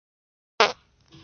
真实的屁 " 屁17
描述：真屁
Tag: 现实 放屁 真正